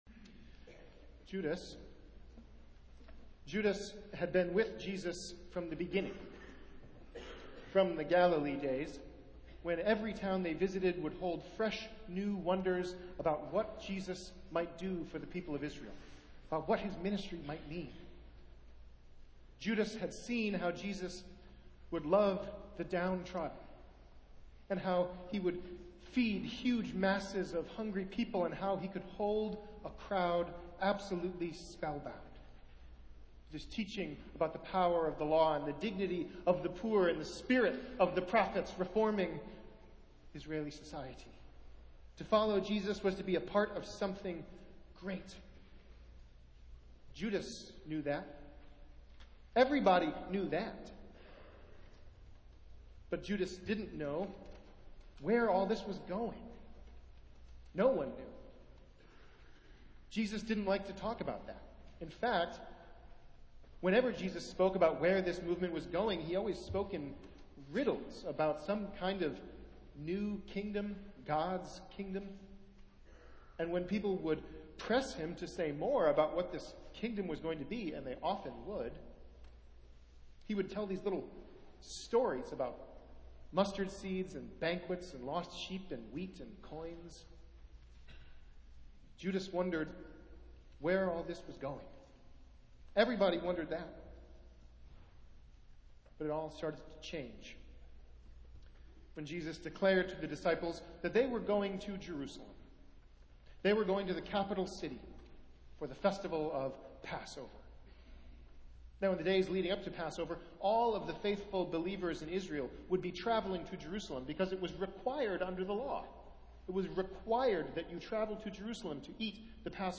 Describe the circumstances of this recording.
Festival Worship - Palm Sunday